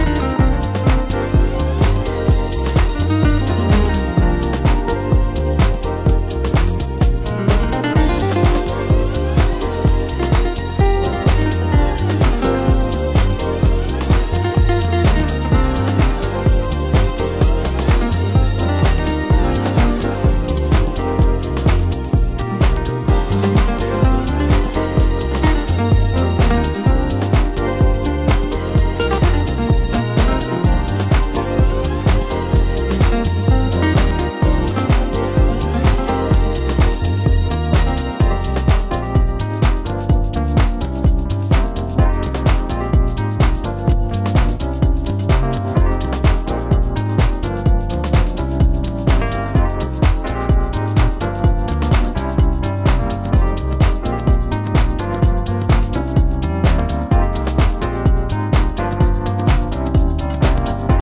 ★DEEP HOUSE 歌